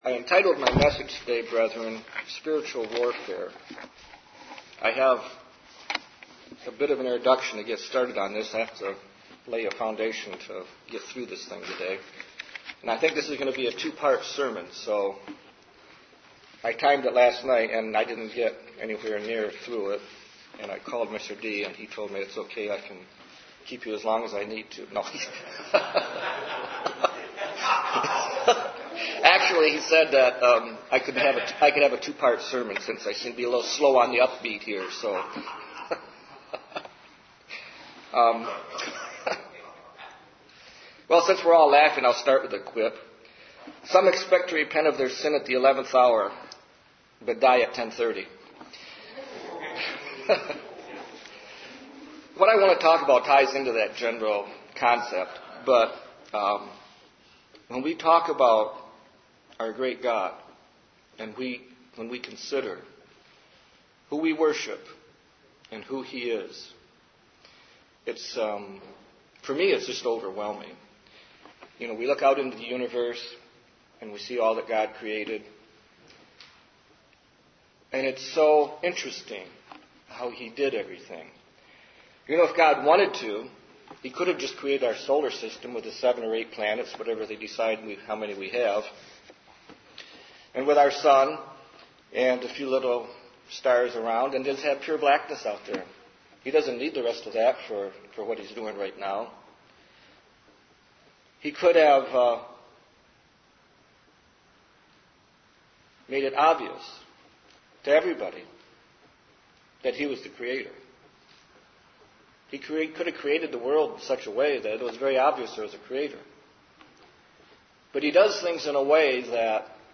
Given in Detroit, MI
UCG Sermon Armor of God Studying the bible?